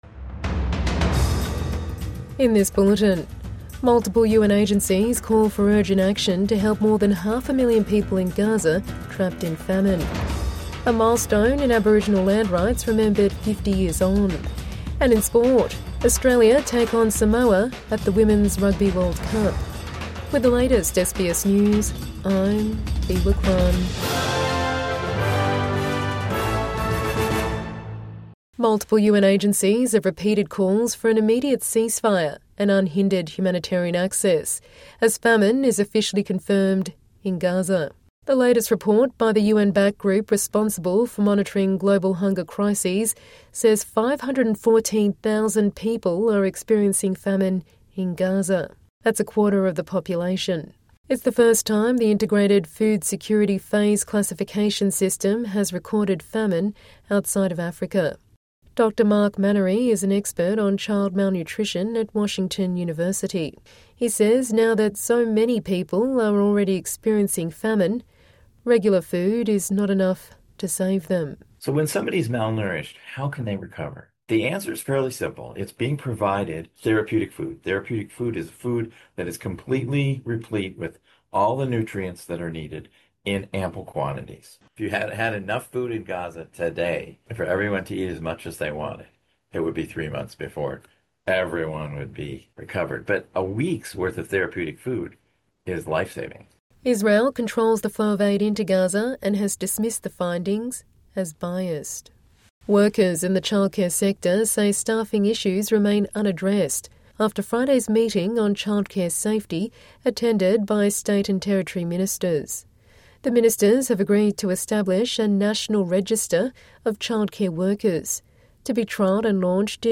Evening News Bulletin 23 August 2025